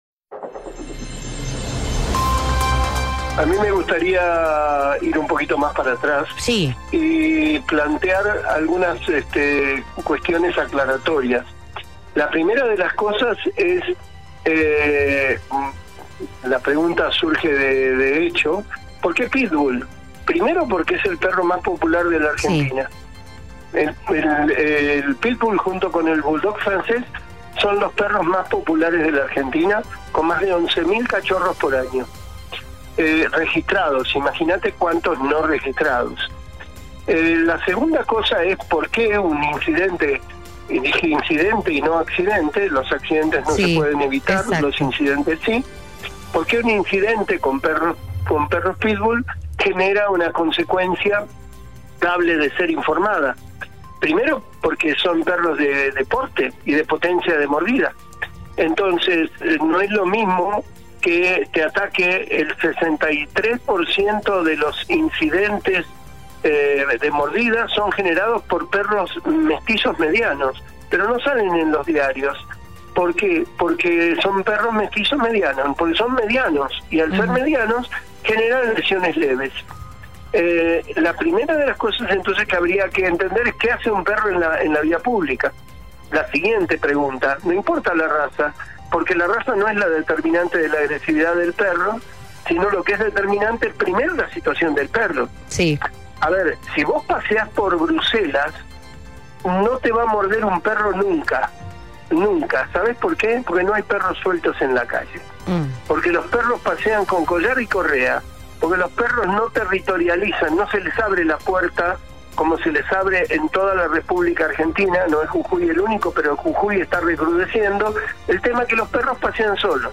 Frente a estas situaciones, donde la mayoría son 100% evitables, recurrimos a un especialista en la materia para saber cómo actuar en el momento donde un perro nos ataca.